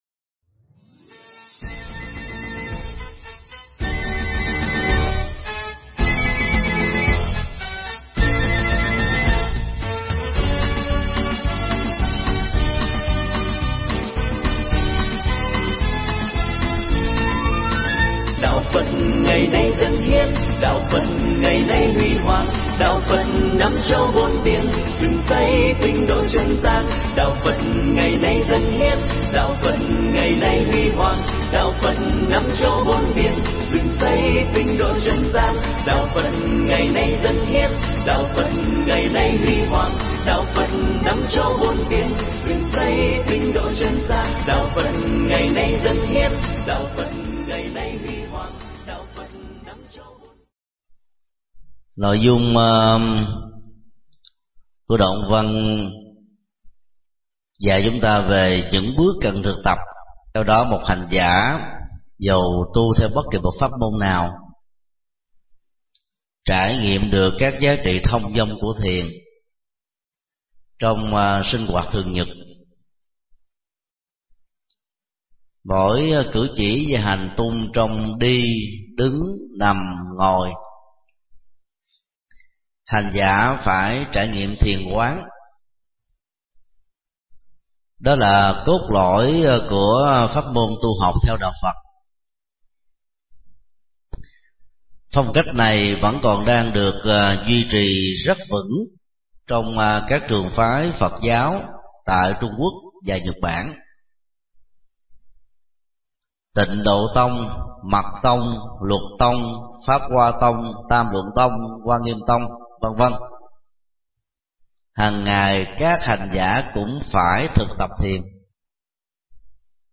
Mp3 Pháp Thoại Quy Sơn Cảnh Sách 6 – Cẩm Nang Thiền Tập – Thầy Thích Nhật Từ Giảng tại trường hạ chùa Sùng Đức, ngày 26 tháng 7 năm 2012